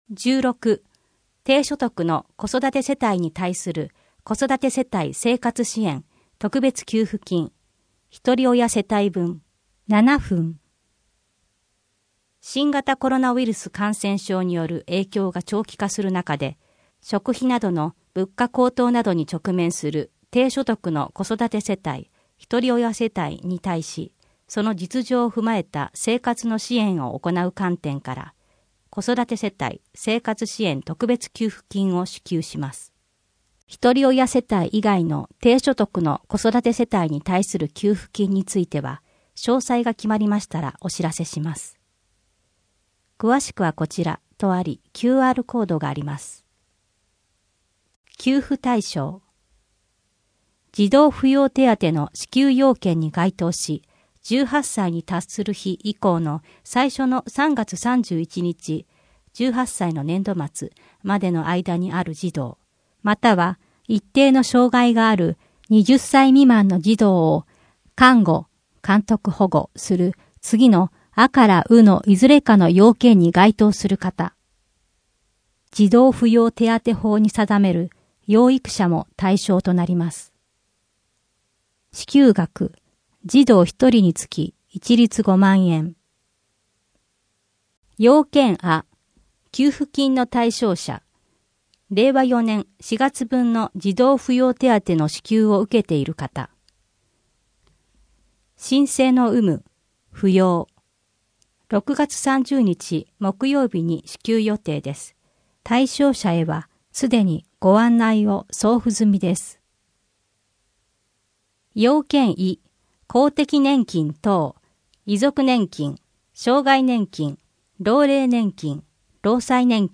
（予約制） さきおり手作り講習会「さきおり布のトートバックをつくりませんか」（予約制） 図書館のイベント 第21回図書館読書交流会 吉行淳之介『漂う部屋』 清瀬けやきホールの催し物 コミュニティプラザひまわりの催し物 多摩六都科学館の催し物 人口と世帯 令和4年6月15日号8面 （PDF 788.4KB） 声の広報 声の広報は清瀬市公共刊行物音訳機関が制作しています。